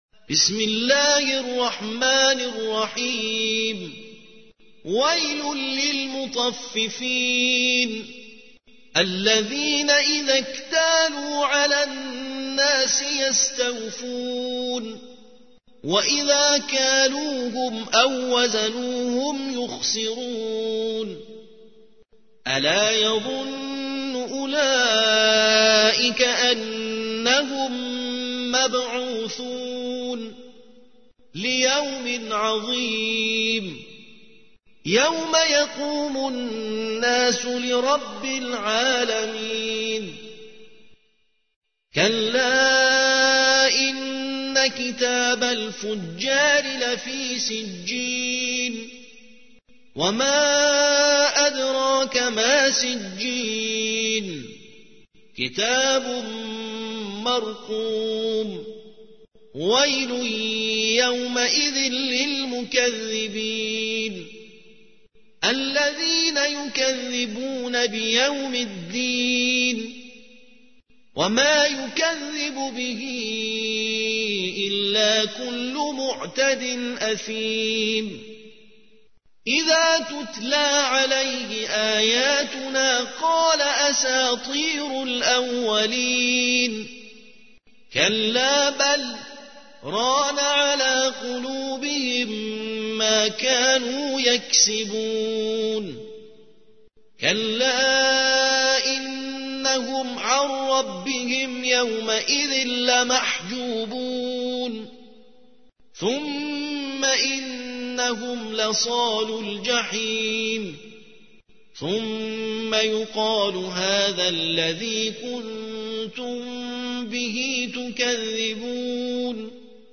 تلاوت ترتیل